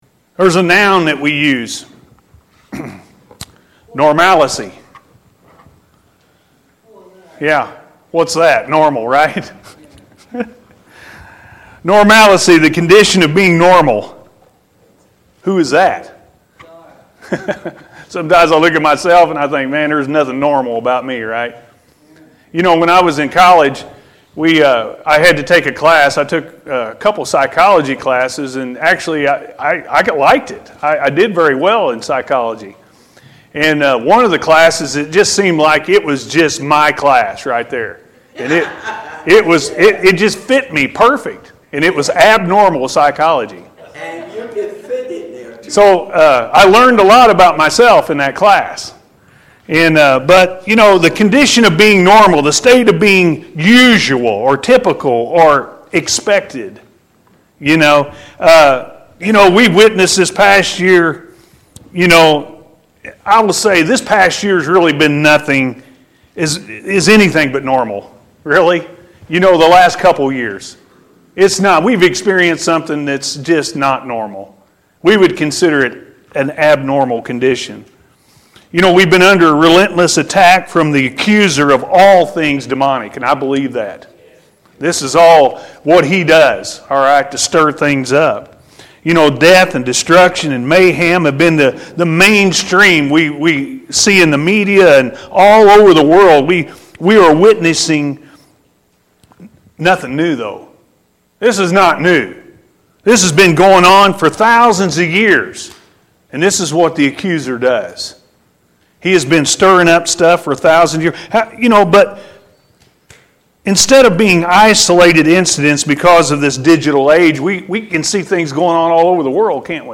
We Can Overcome-A.M. Service